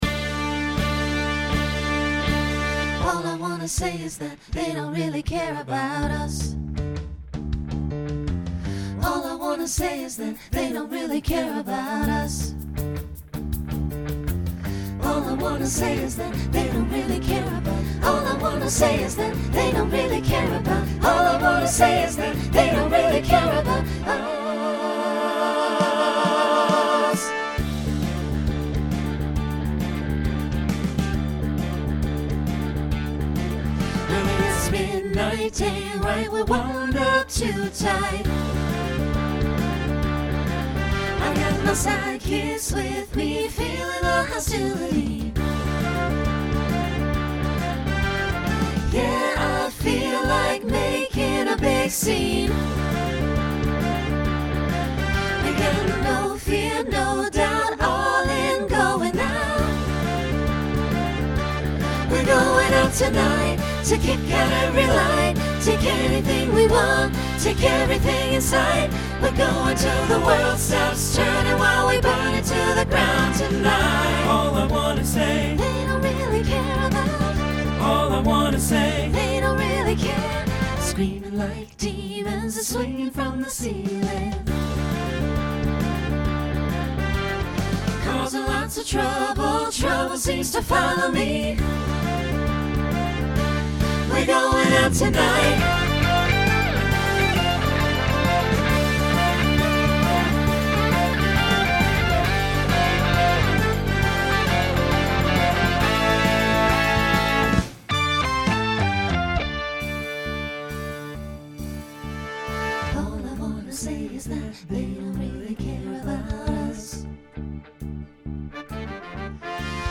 Built-in bow included.
Pop/Dance , Rock
Voicing SATB